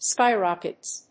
/ˈskaɪˌrɑkʌts(米国英語), ˈskaɪˌrɑ:kʌts(英国英語)/